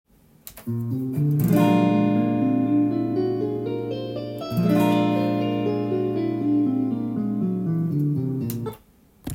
おしゃれな曲で使われる変わった響きがするスケール
A♯またはB♭ホールトーンスケール